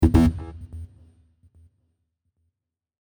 Buttons and Beeps
Error 1.mp3